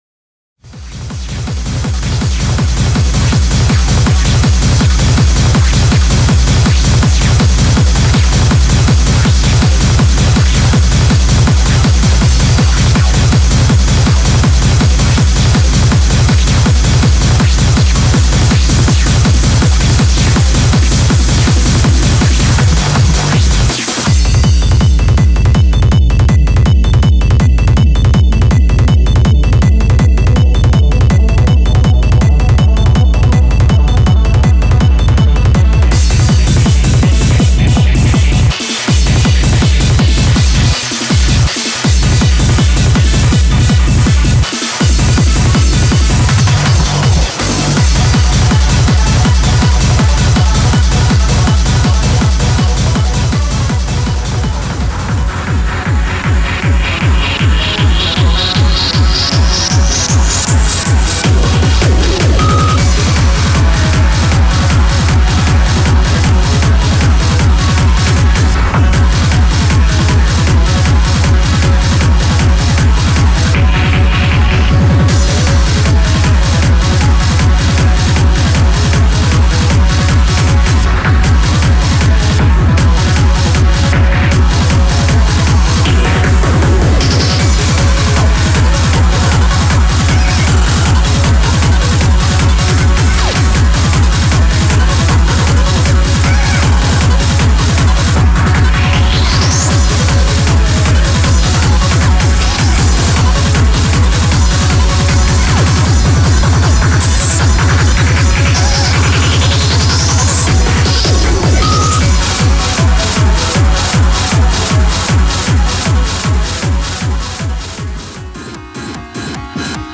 全曲クロスフェードデモ(MP3 256kbps)